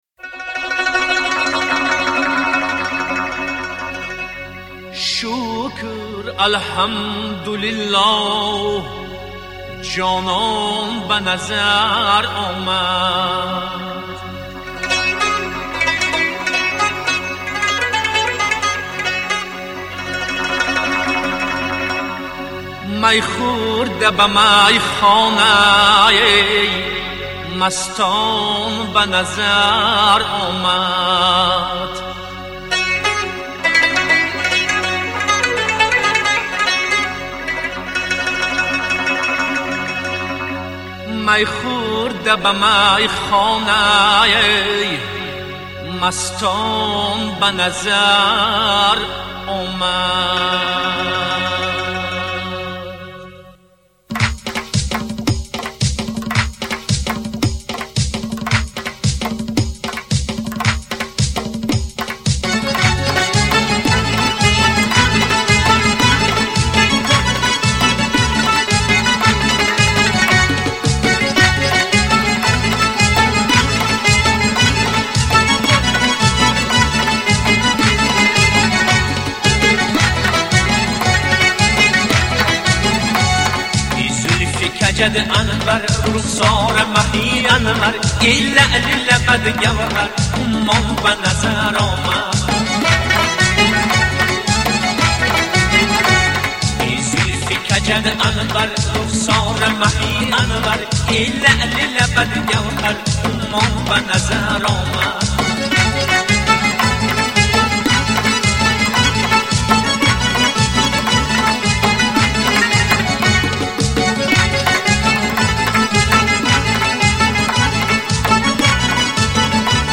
Категория: Халки-Народный